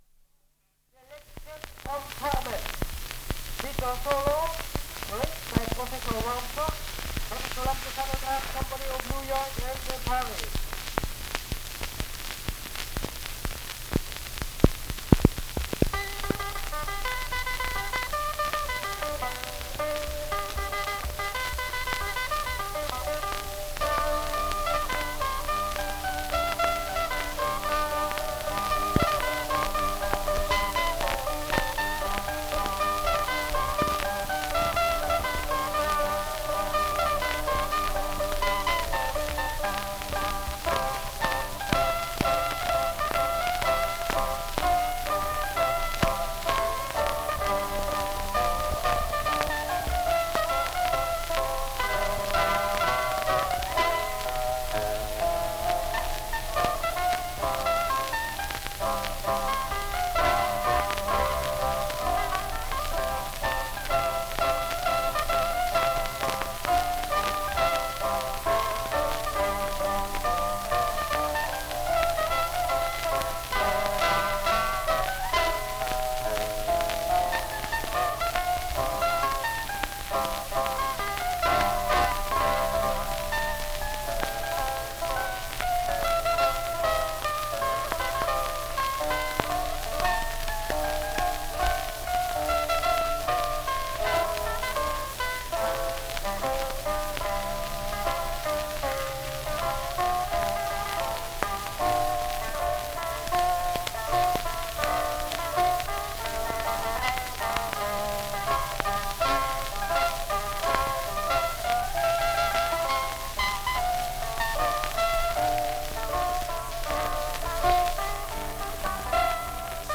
Selection from Carmen (Zither solo)
Ljudinspelningar från omkring 1900